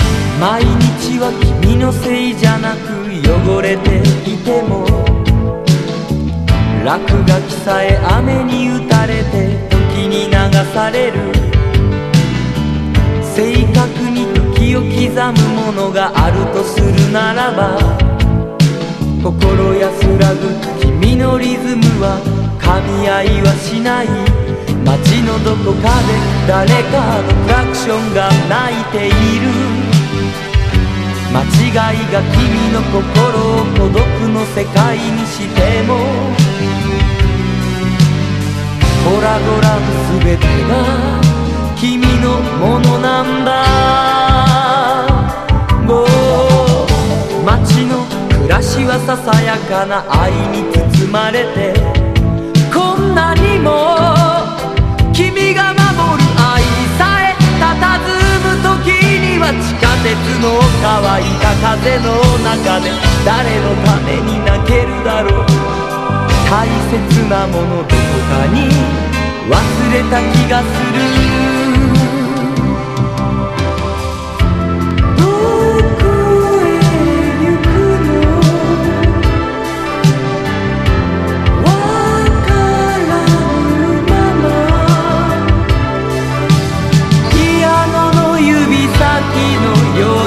COMIC SONG / JAPANESE DISCO
テクノ歌謡的アレンジもばっちり
燃えるルンバ・ディスコ